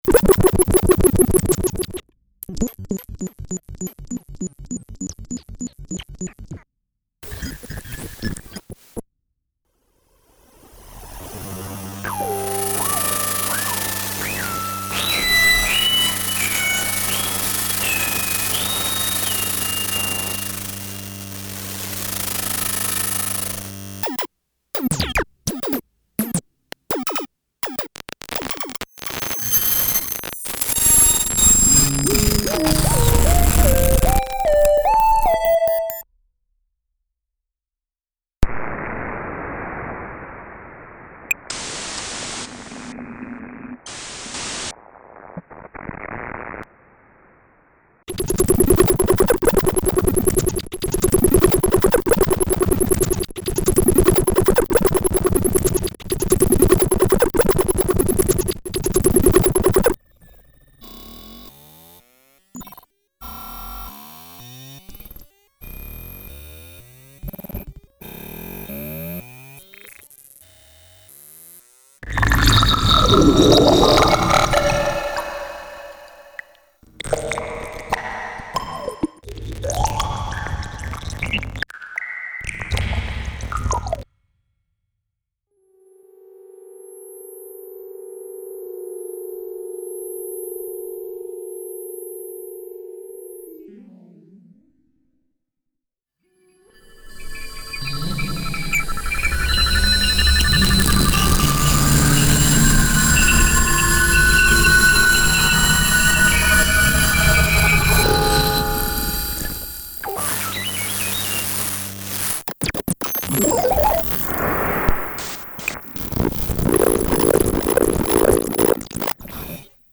9 Planets (2004) – stereo playback & video projection